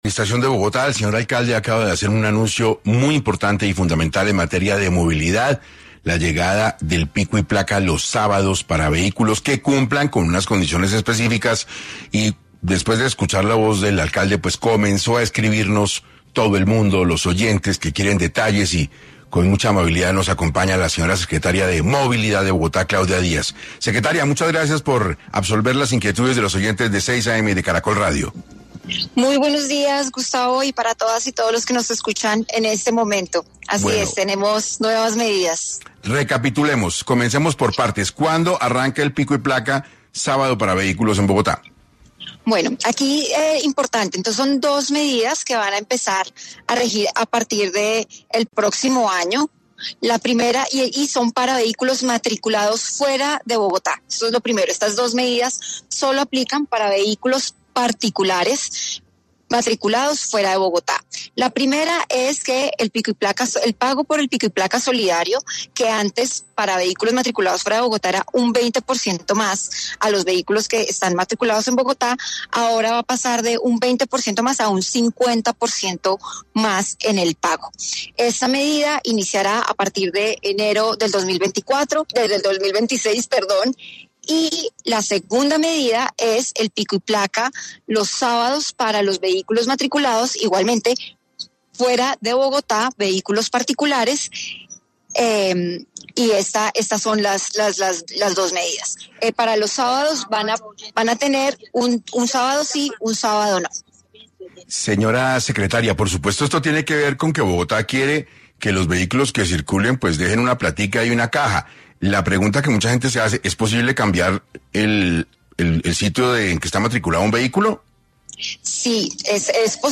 La medida de pico y placa solidario aumentará para estos vehículos del 20% al 50%. Así lo aclaró el alcalde de Bogotá y la secretaria de Movilidad, Claudia Díaz, quien estuvo en 6 AM, de Caracol radio